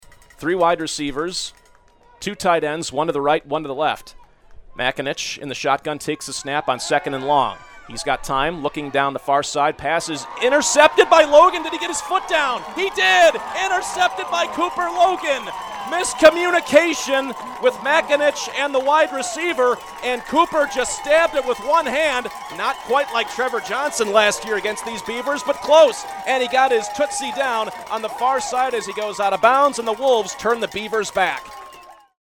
as heard on Fox Sports Aberdeen: